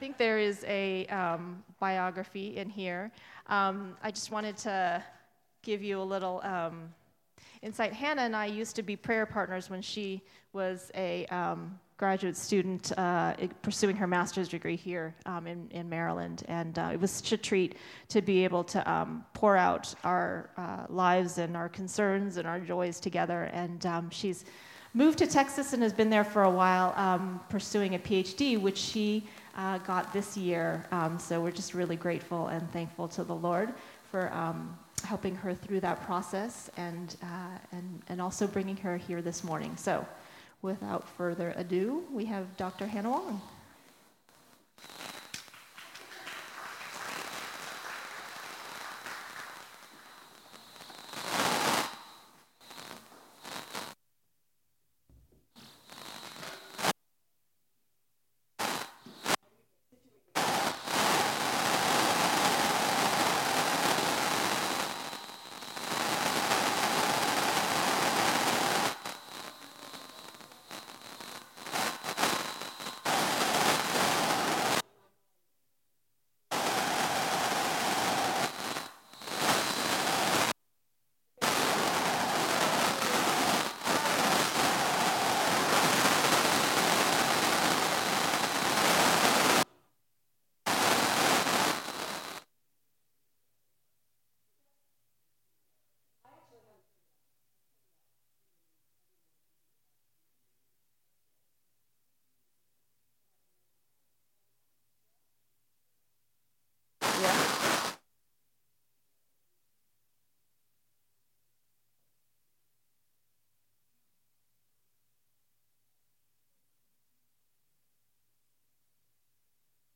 Due to technical difficulties, the final minutes of the sermon could not be recorded.